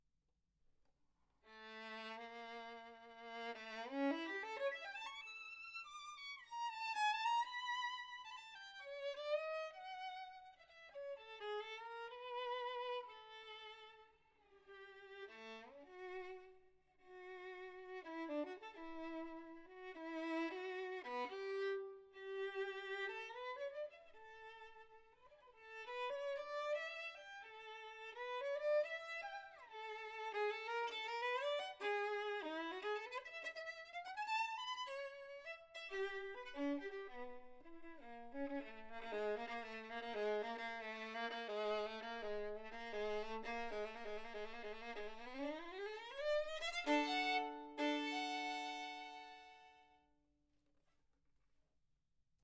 Här kommer en bättre ljudfil från mitt sovrum ;)
Det som låter som dist var stråken, spände inte upp den tillräckligt...
(Se'n slog jag i pallen som ljudkortet står på - ni får ta det som det är!)
Jag tycker du har hittat en mikposition som funkar bra. Tydligt och snyggt utan att bli trattigt vasst och jobbigt. Det låter ju inte som på flera meters avstånd, men förmodligen mycket mer realistiskt än genom hörselskydden.
Du kan vara lugn, ORTF ca 35cm ovanför fiolen
Fylligheten tycker jag kom med ORTF.